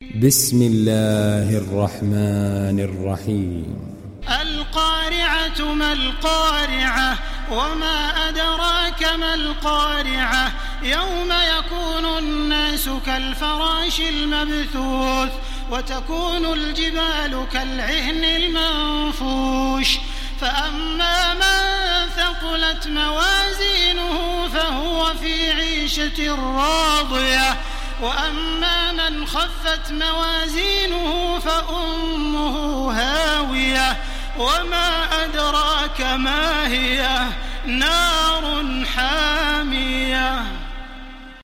ডাউনলোড সূরা আল-ক্বারি‘আহ্ Taraweeh Makkah 1430